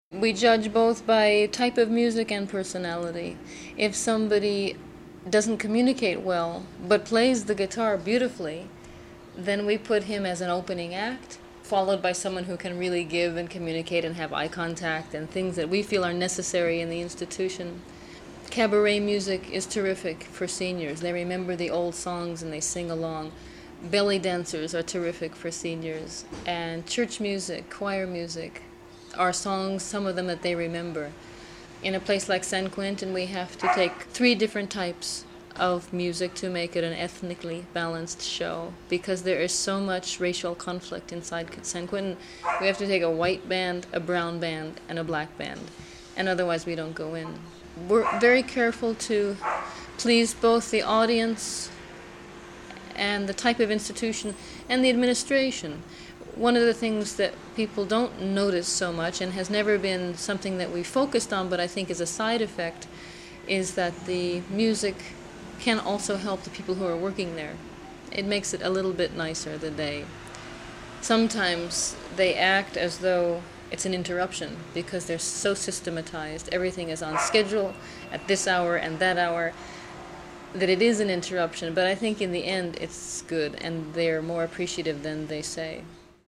INTERVIEWS WITH MIMI:
for German public radio